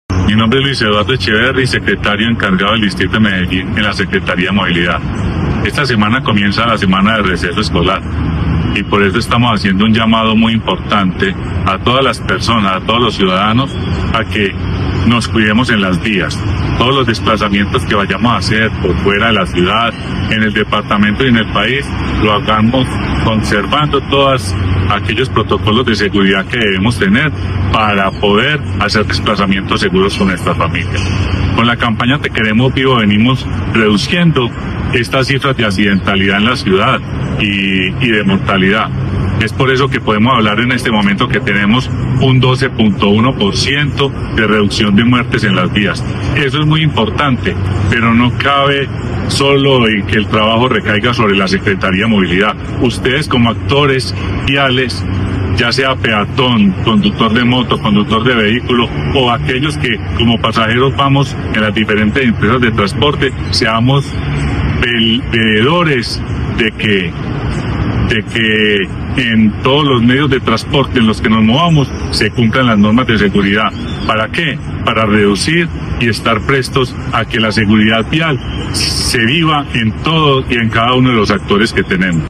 Declaraciones secretario (e) de Movilidad, Luis Eduardo Echeverri
Declaraciones-secretario-e-de-Movilidad-Luis-Eduardo-Echeverri.mp3